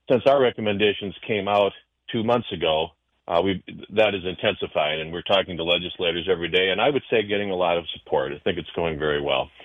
He sounds confident going into the special session.